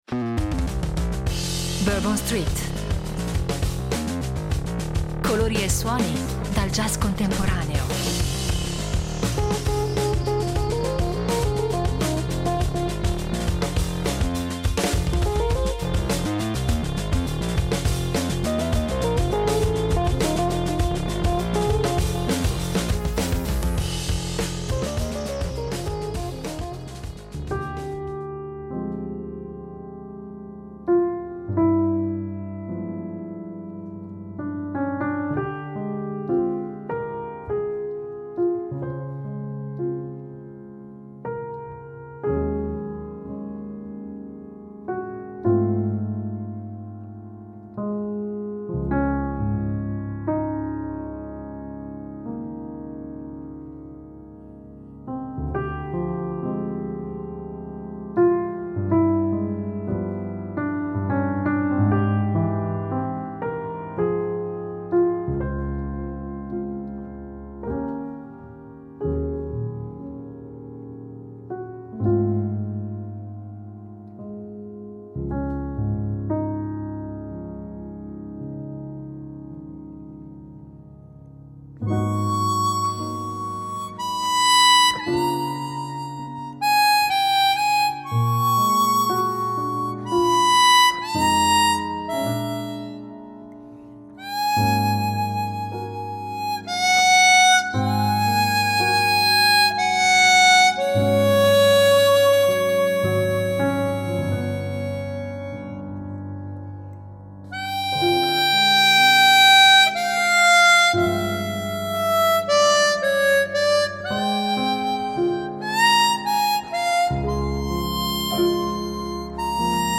Talenti del jazz italiano